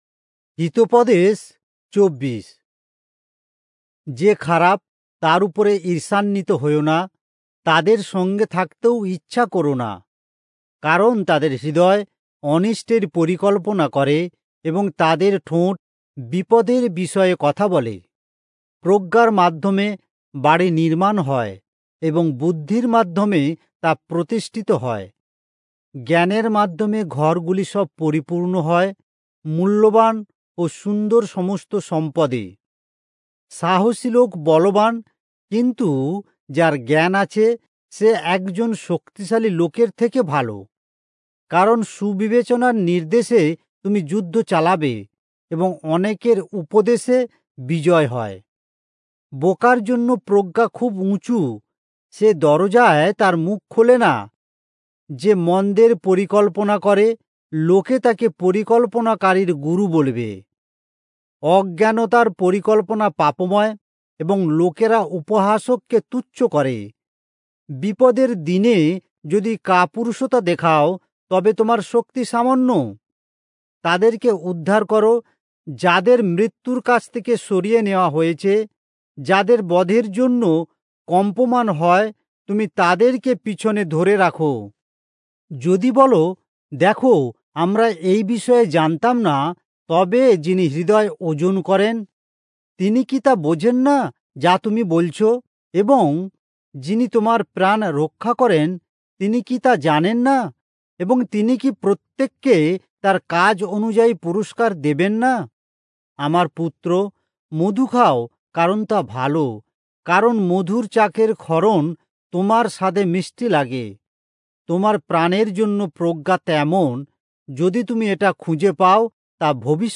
Bengali Audio Bible - Proverbs 14 in Irvbn bible version